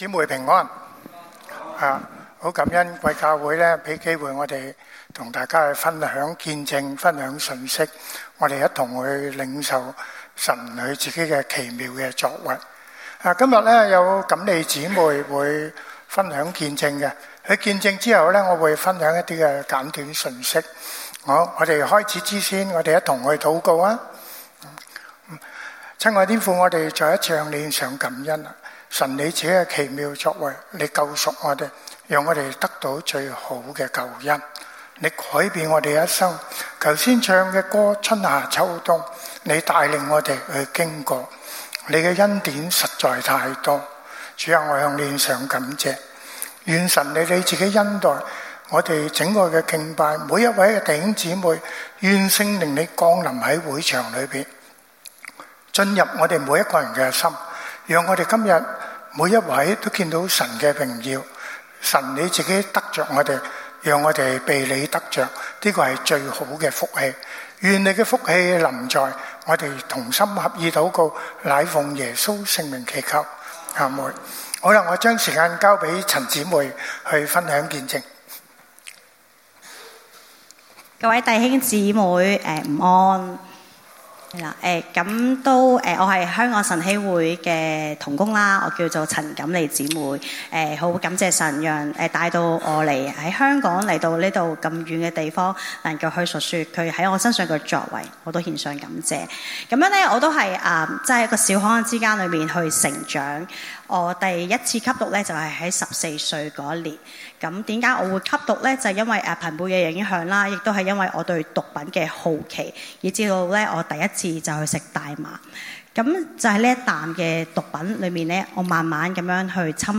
Sermon – 第 3 頁 – 澳亞基督教會 Austral-Asian Christian Church